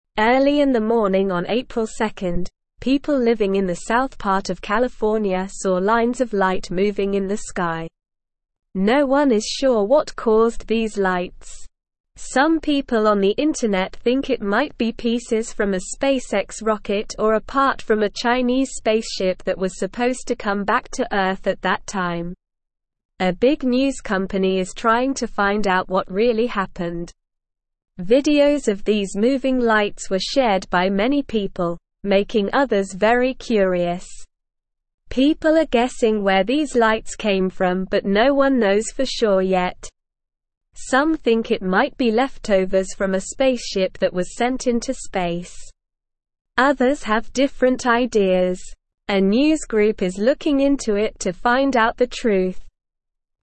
Slow
English-Newsroom-Beginner-SLOW-Reading-Mysterious-Lights-in-California-Sky-What-Happened.mp3